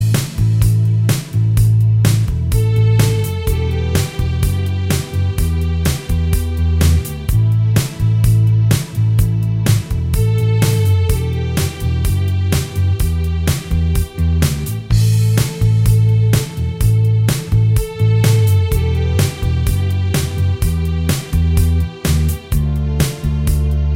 Minus Guitars Indie / Alternative 4:27 Buy £1.50